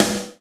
Index of /90_sSampleCDs/Roland L-CDX-01/KIT_Drum Kits 5/KIT_Induced Kit
SNR HARD 04L.wav